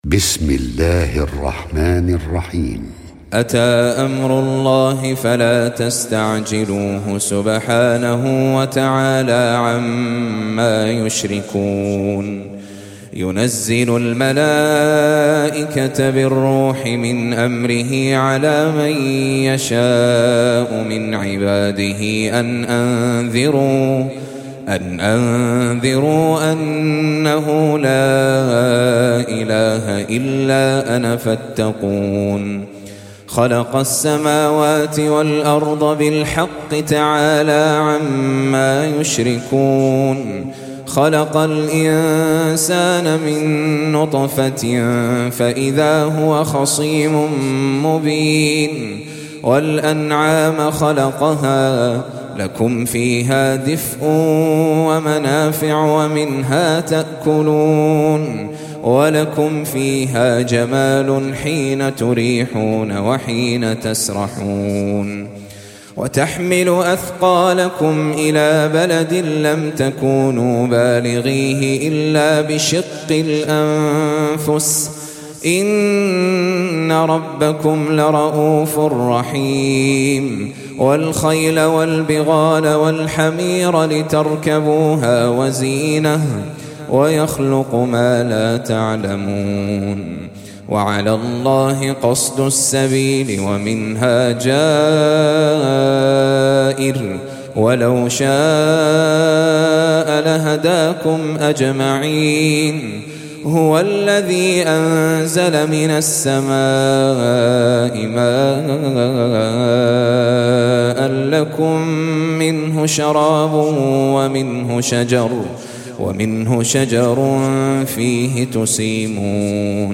16. Surah An-Nahl سورة النحل Audio Quran Tajweed Recitation
Surah Sequence تتابع السورة Download Surah حمّل السورة Reciting Murattalah Audio for 16.